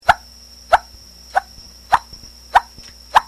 ここは鳴り砂の浜といっても、注意して歩かねば気づかないような浜であるが、今日は今までと比較して感度がよく、「キュッ、キュッ」と音がした。音のスペクトル解析からも今日の砂は良い音であることがわかった。
平成14（2002)年８月30日の黒松海岸の砂
プロットをクリックして、鳴り砂の音をお聞きください。